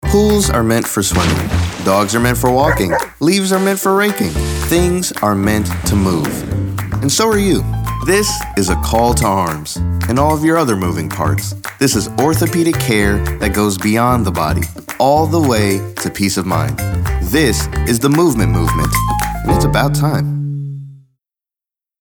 caring, compelling, concerned, conversational, friendly, genuine, inspirational, motivational, soft-spoken, warm